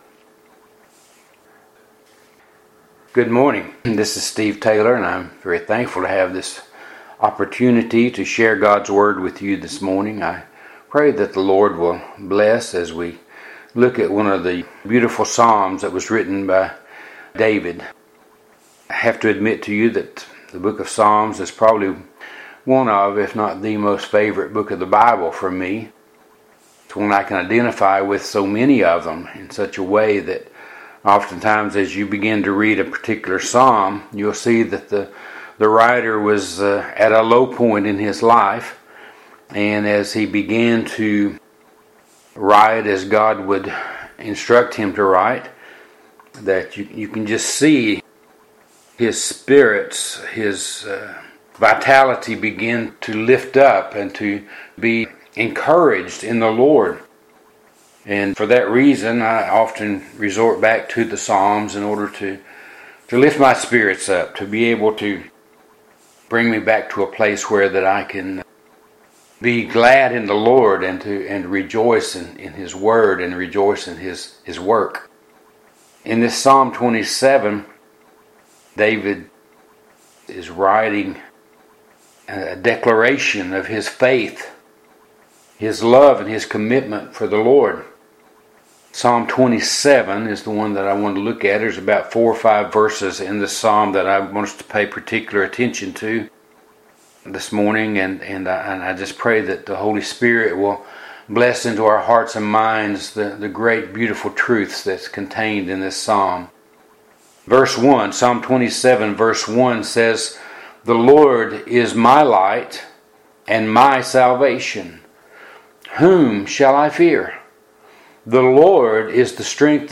Today's Sermon